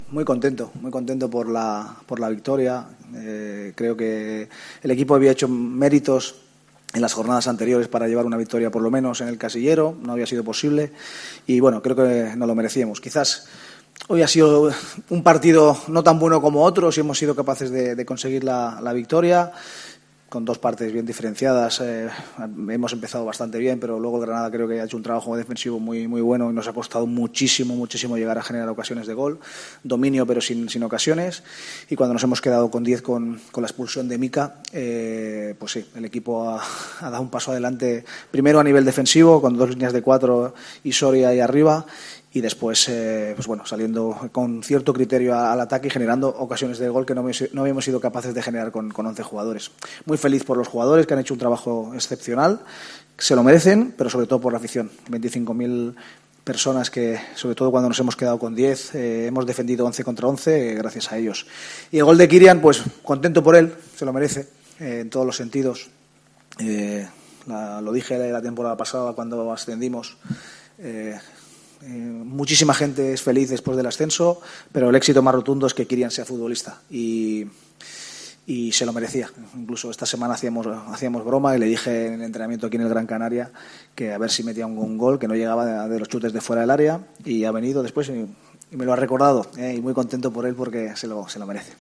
García Pimienta compareció en la sala de prensa del Estadio Gran Canaria tras la victoria, ante el Granada CF (1-0).